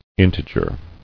[in·te·ger]